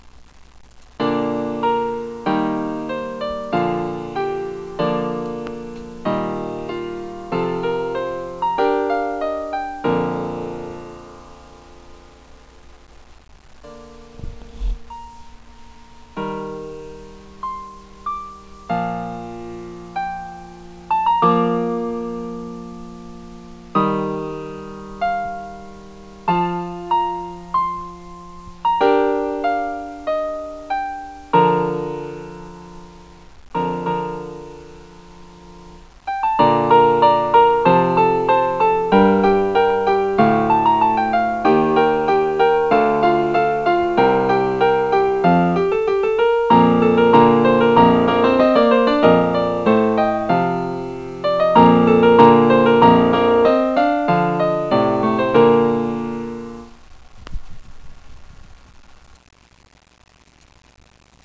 And I lack any advanced knowledge to use anything but Sound Recorder to record the song. Therefore, it isn't great quality.